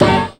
SWINGSTAB 11.wav